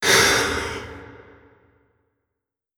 Jumpscare_13.wav